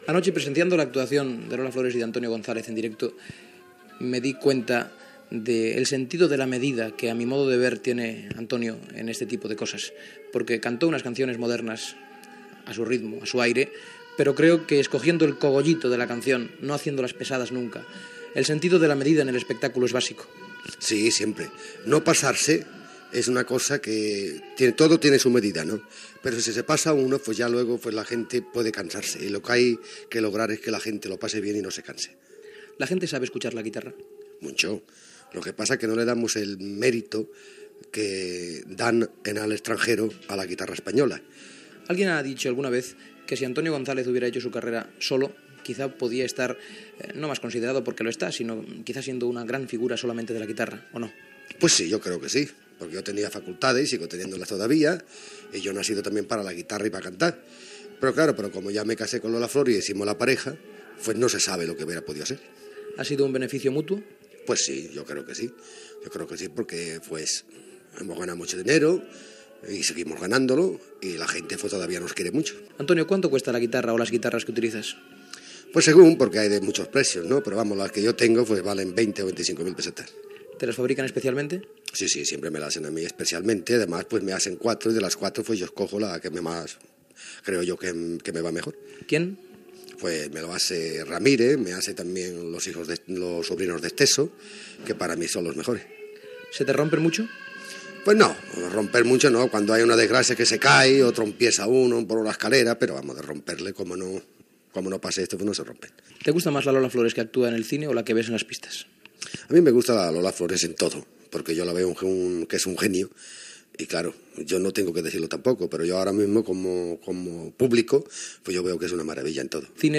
Entrevista al guitarrista Antonio González "El Pescadilla", que actuava a Barcelona amb la cantant Lola Flores
Entreteniment